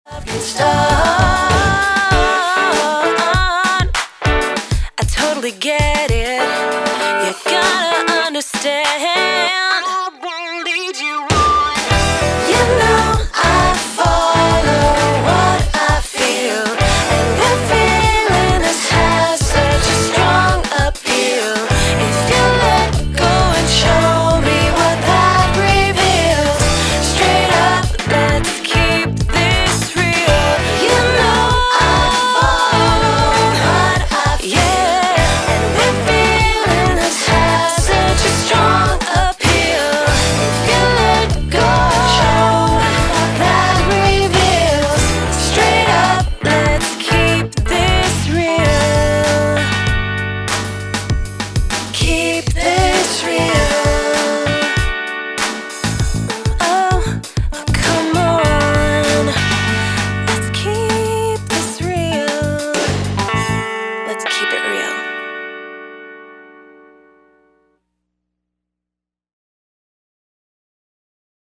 pop artist
With strong rhythms and